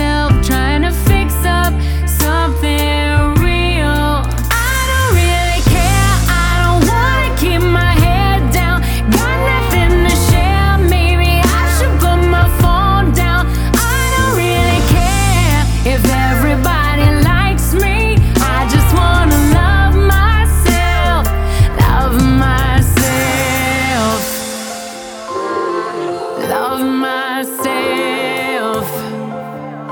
Genre: Soundtrack